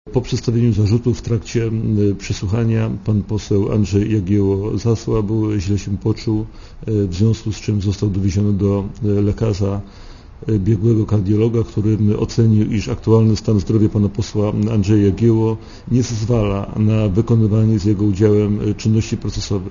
Dla Radia Zet mówi prokurator Olejnik